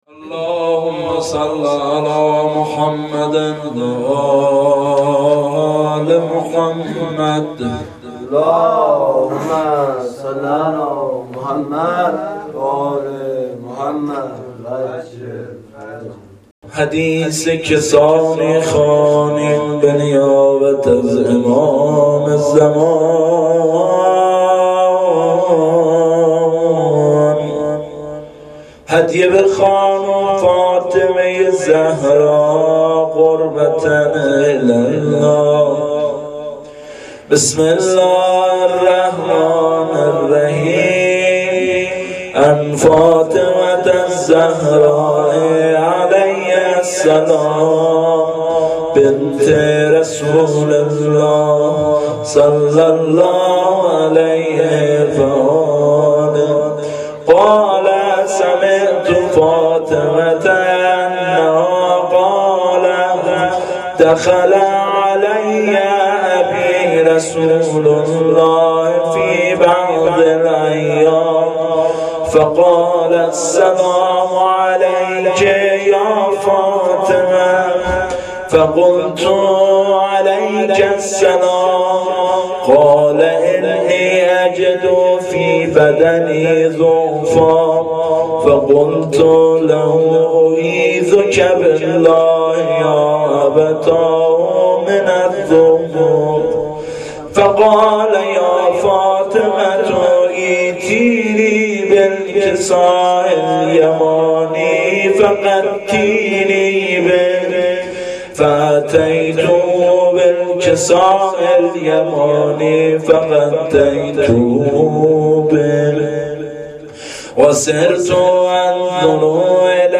هیئت رایت الهدی کمالشهر
مداحی فاطمیه